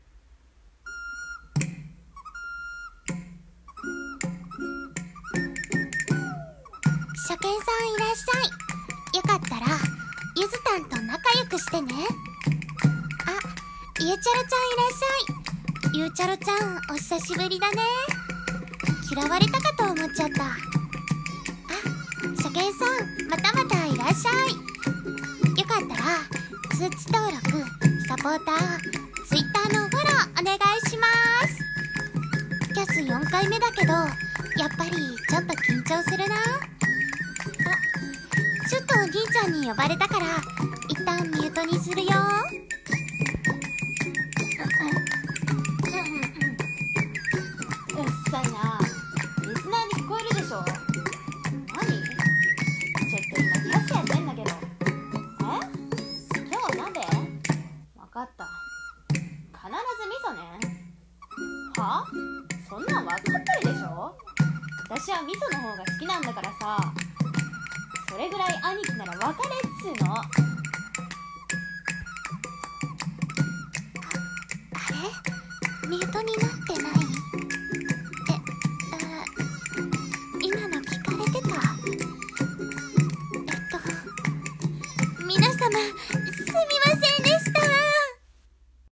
【声劇】萌え声が地声バレる【1人声劇】